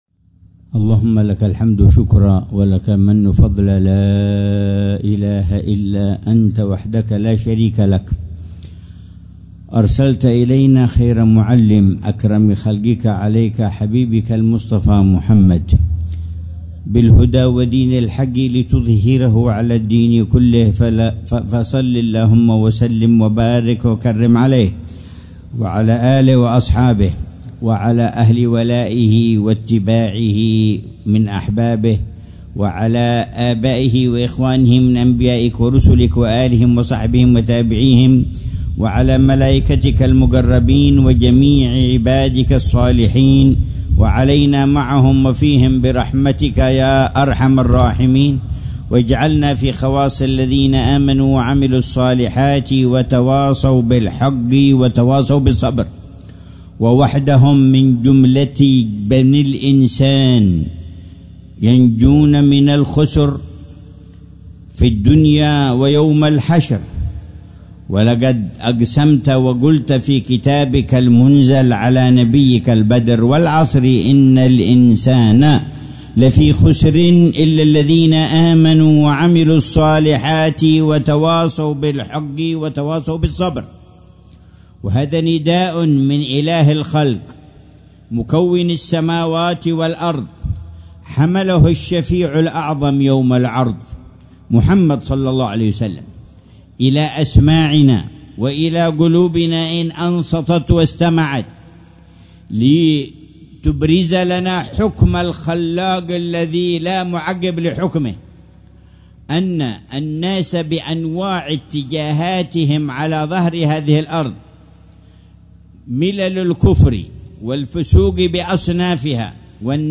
محاضرة العلامة الحبيب عمر بن محمد بن حفيظ في جلسة الجمعة الشهرية الـ54، في ساحة الخياط بحارة السحيل، مدينة تريم، ليلة السبت 21 جمادى الأولى 1446هـ، بعنوان: